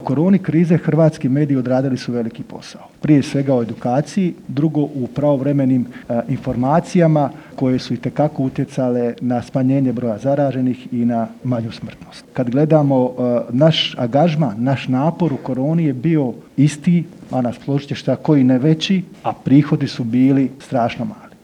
ZAGREB - U organizaciji AMM GLOBAL INSTITUTA održan je prvi CROATIA MEDIA CONGRESS na temu izmjena Zakona o elektroničkim medijima.